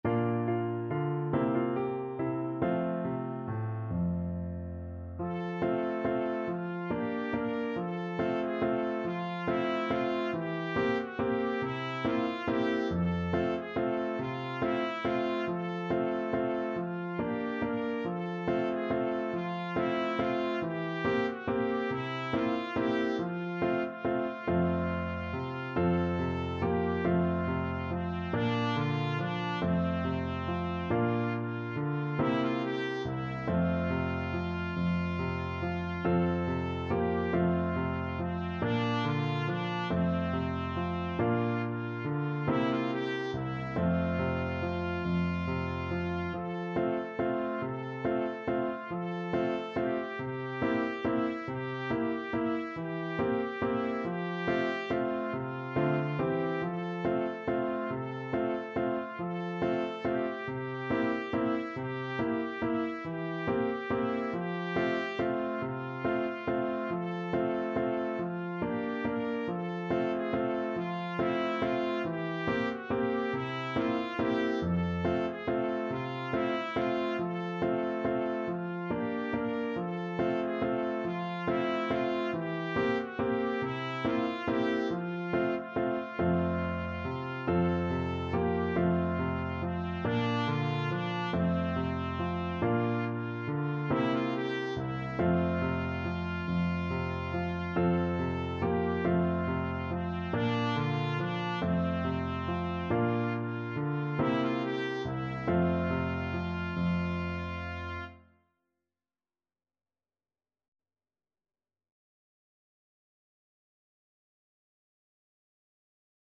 E Papa Waiari Free Sheet music for Trumpet
Time Signature: 6/8
Tempo Marking: Steadily = 140 Score Key: F major (Sounding Pitch)
Range: C5-Bb5
Style: Traditional